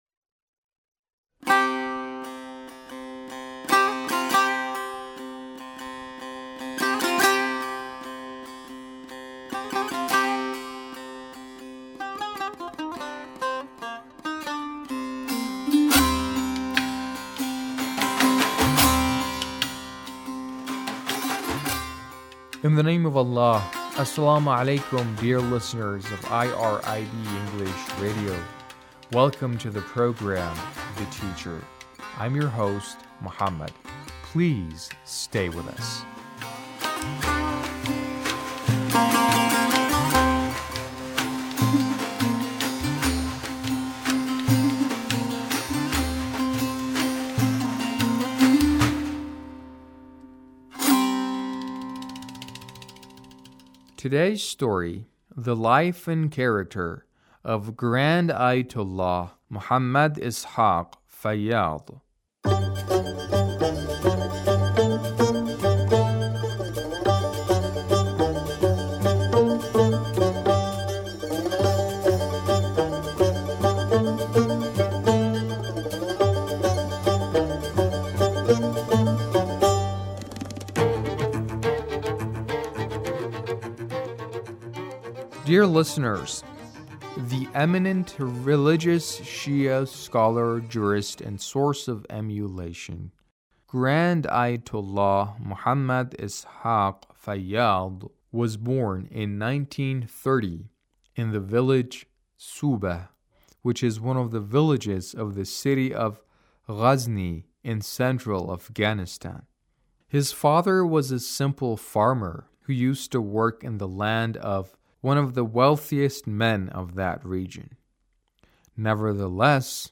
A radio documentary on the life of Ayatullah Ishaq Fayyaz